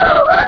Cri d'Absol dans Pokémon Rubis et Saphir.